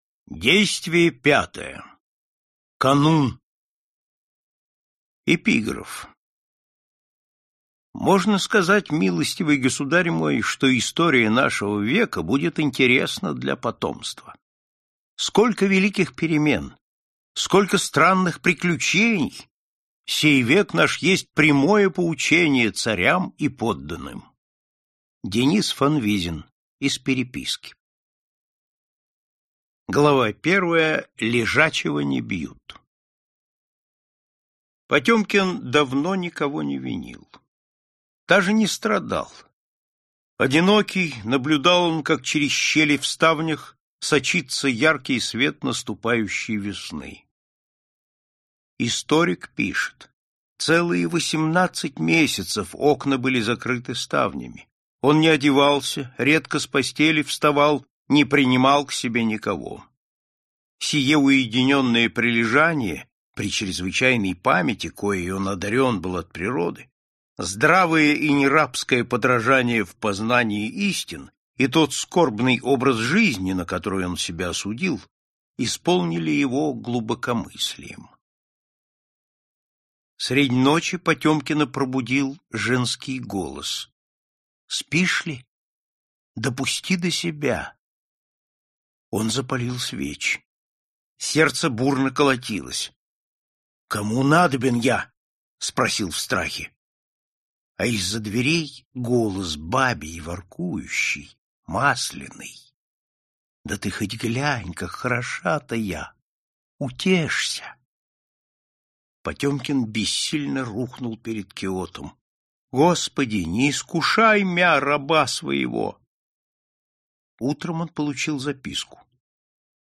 Аудиокнига Фаворит (часть 2) | Библиотека аудиокниг